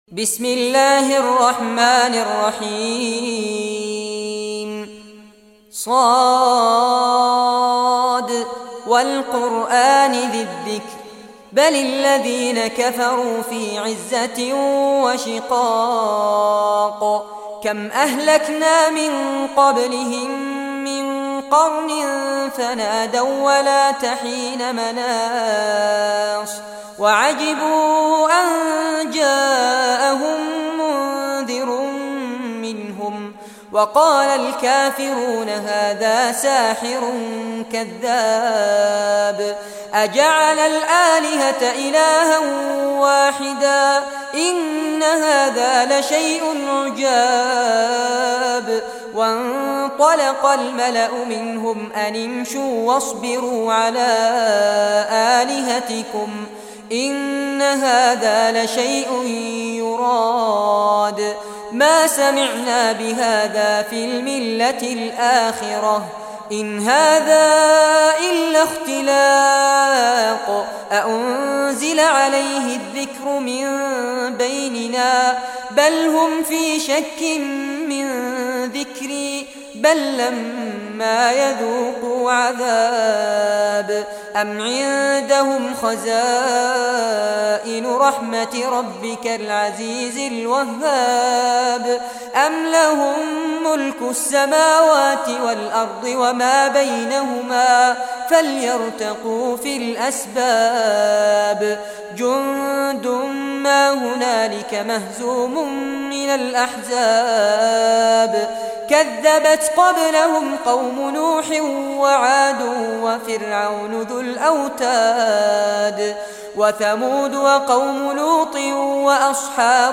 Surah Sad Recitation by Fares Abbad
Surah Sad, listen or play online mp3 tilawat / recitation in Arabic in the beautiful voice of Sheikh Fares Abbad.